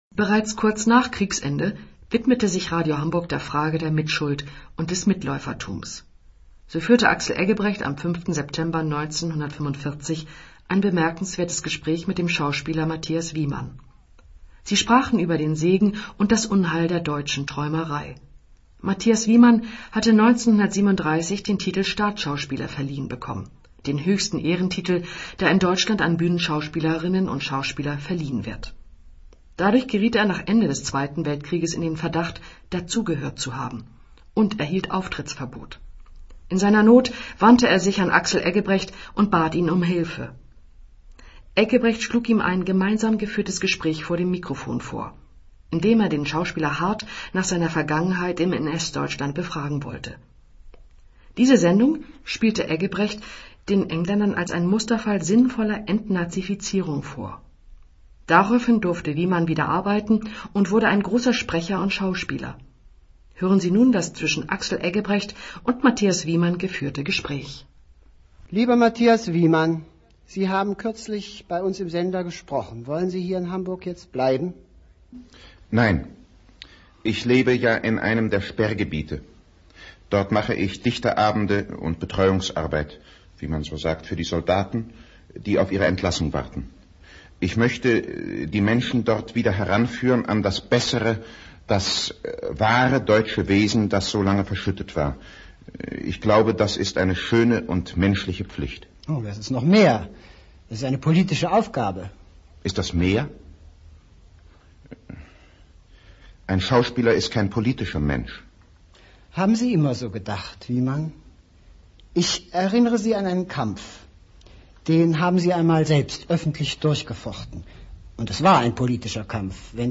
NWDR-Rundfunkinterview vom 5. September 1945: Mathias Wieman und Axel Eggebrecht sprechen über Segen und Unheil der deutschen Träumerei. Sendeform: Gespräch.(Sign.